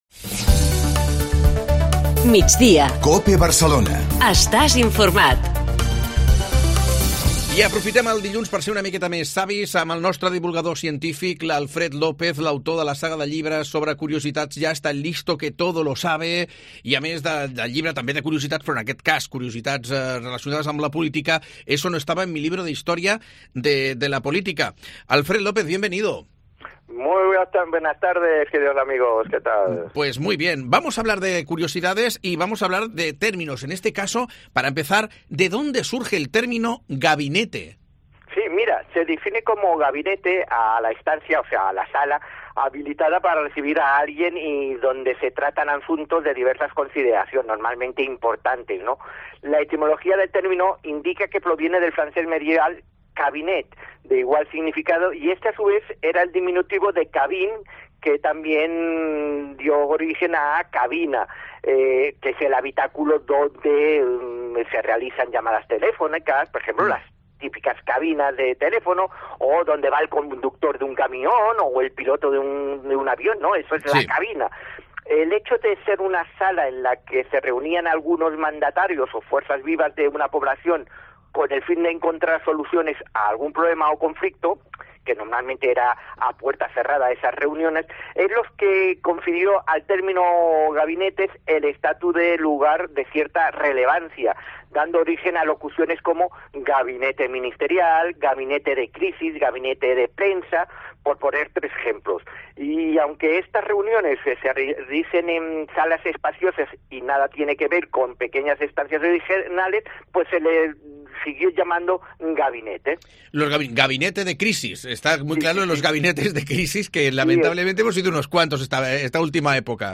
Cada lunes nos explica, en La Linterna Catalunya, unas cuantas de ellas.